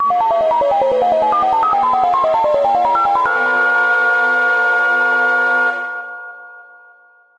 Category: Games Soundboard